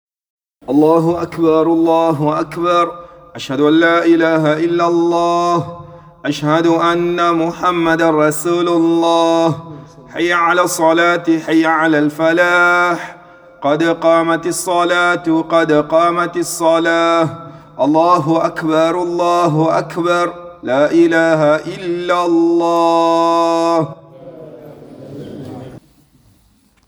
7)  Eqama (Call to start prayer)